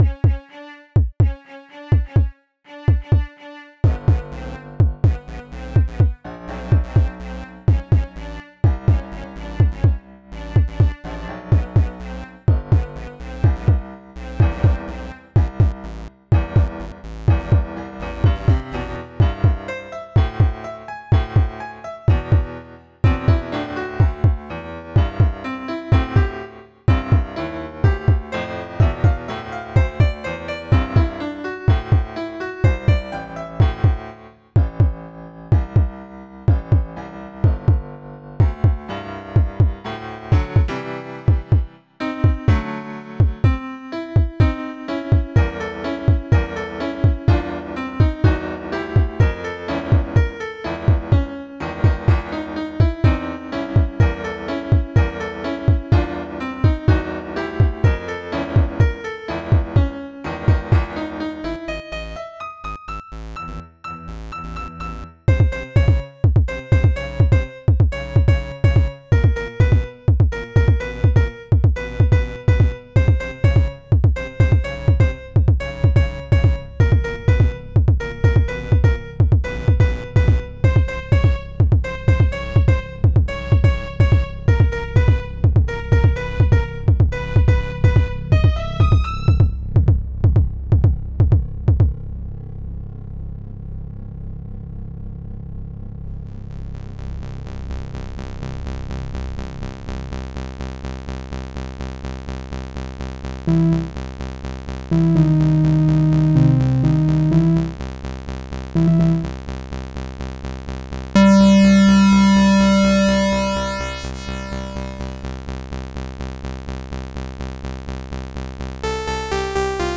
"This track is an emotional ode to Earth's discovery of the